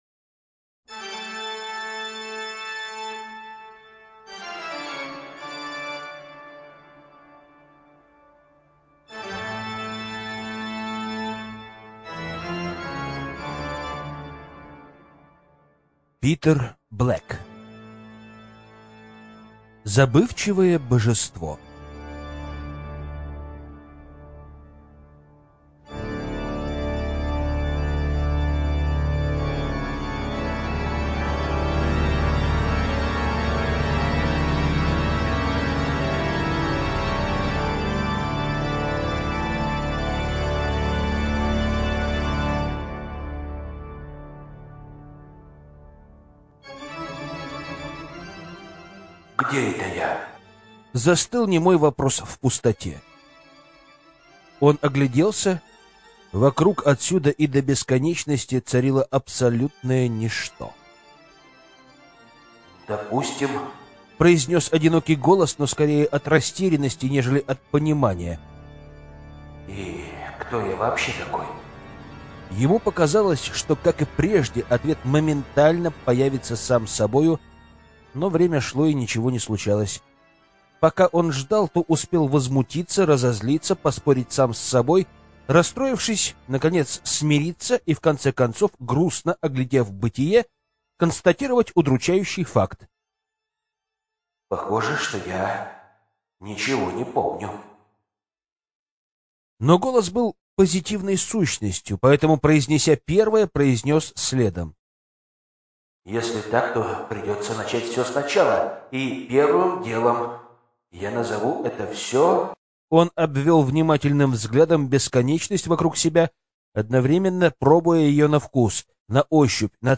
Аудиокнига Забывчивое божество | Библиотека аудиокниг